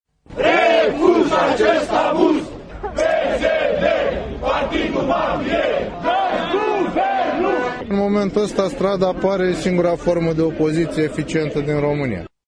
vox-scurt.mp3